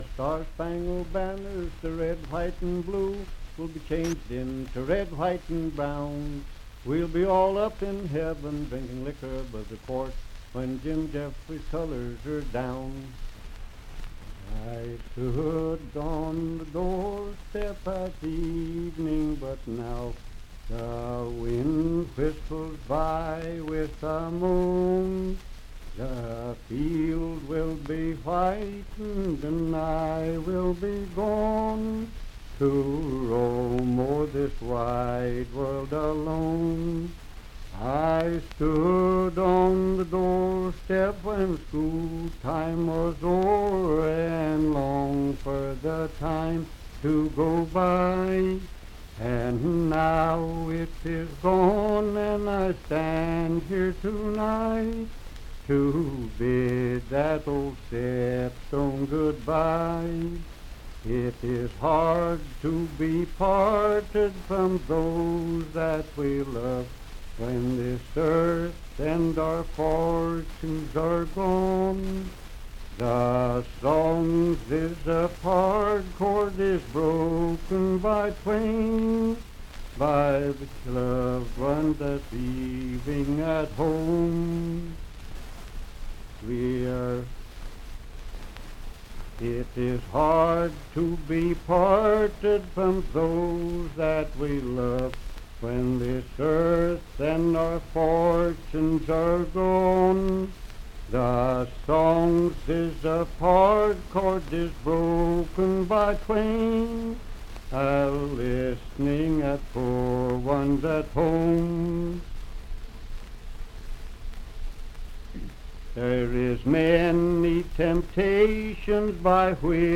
Unaccompanied vocal music
Verse-refrain 6(4-8).
Voice (sung)
Pendleton County (W. Va.), Franklin (Pendleton County, W. Va.)